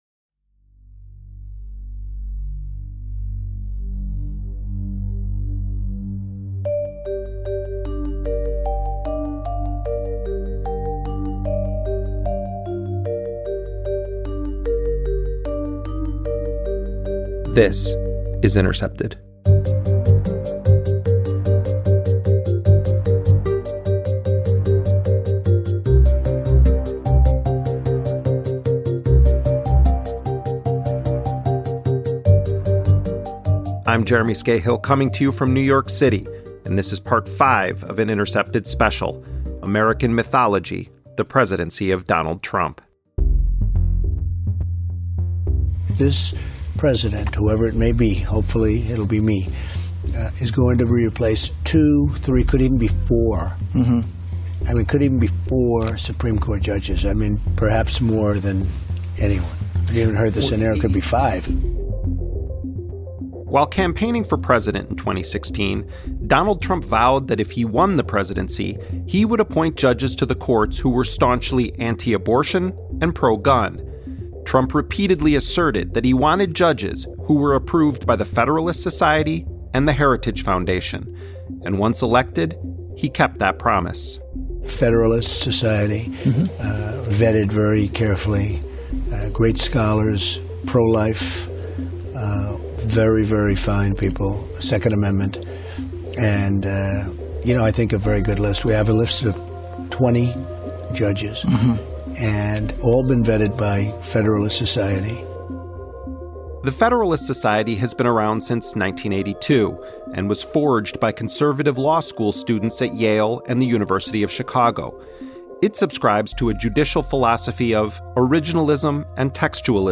An Intercepted audio documentary series offers a comprehensive analytical history of the Trump presidency.